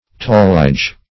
Tallage \Tal"lage\, Talliage \Tal"li*age\, n. [F. taillage. See